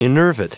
Prononciation du mot enervate en anglais (fichier audio)
Prononciation du mot : enervate